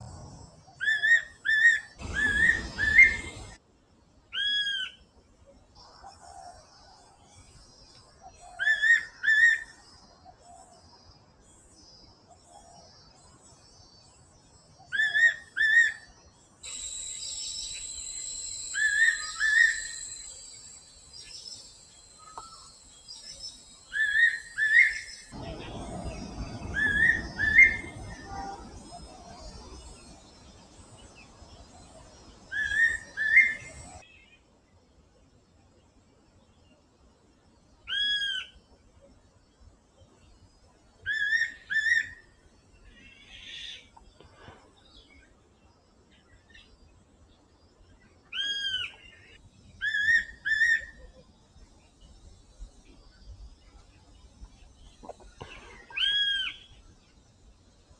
仙八色鸫求偶叫声